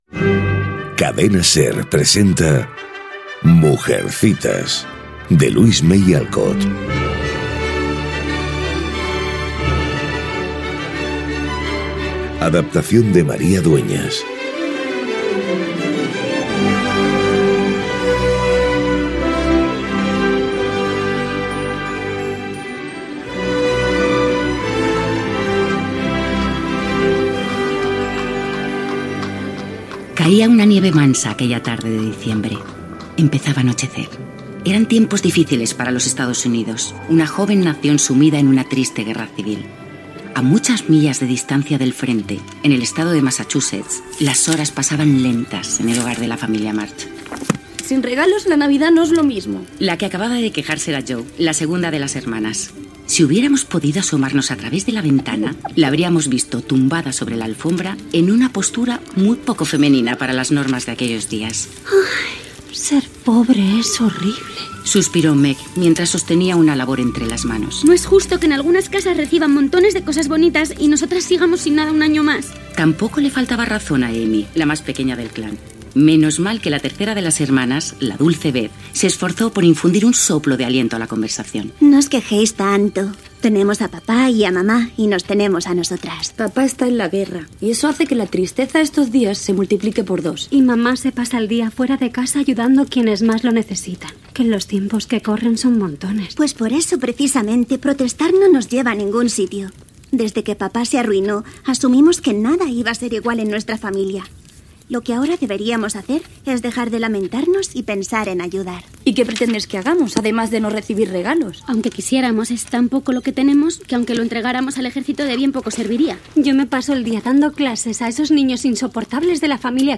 Adaptació radiofònica de "Mujercitas" de Louisa May Alcott, per María Dueñas. Careta del programa, la narradora situa l'acció i primeras escenes
Ficció